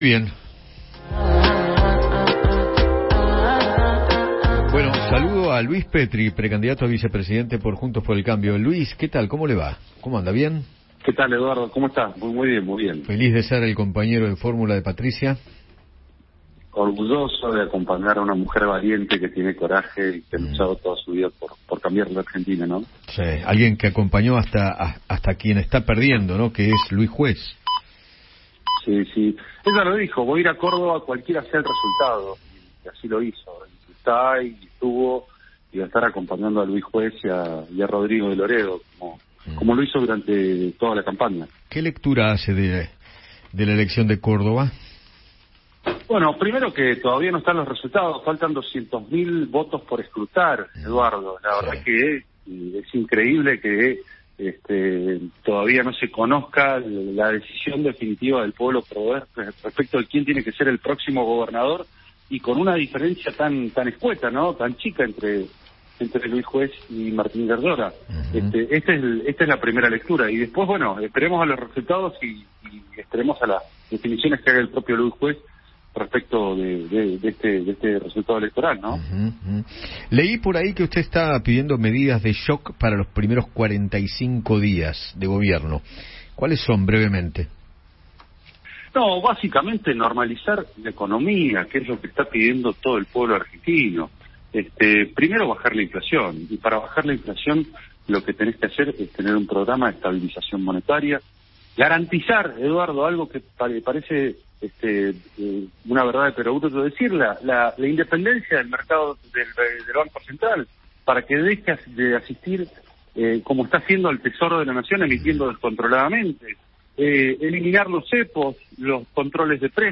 Luis Petri, precandidato a vicepresidente de Juntos por el Cambio, dialogó con  Eduardo Feinmann sobre su propuestas en caso de ser elegido en las urnas.